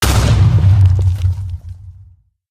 explode1.ogg - 25w18a
explode1.ogg